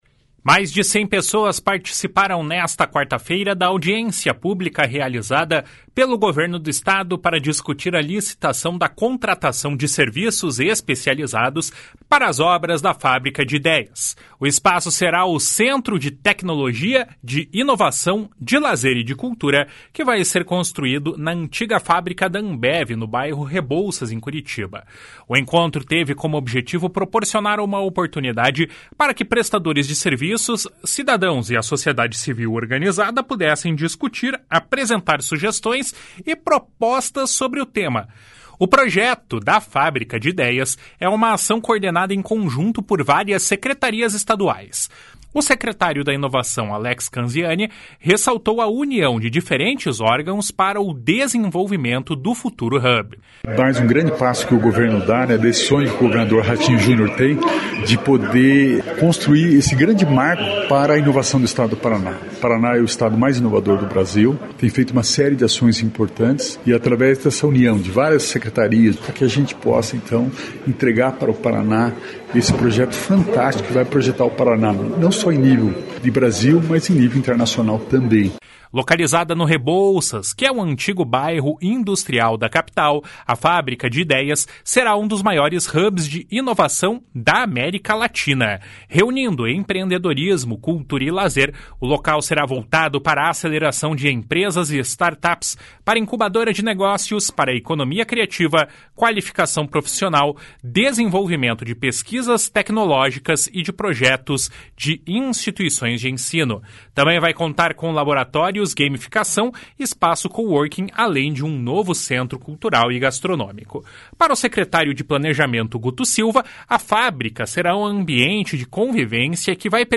audiencia_publica_da_fabrica_de_ideias.mp3